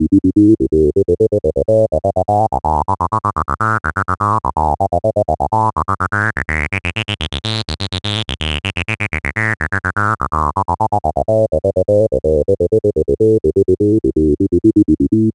cch_acid_loop_acidwave_125.wav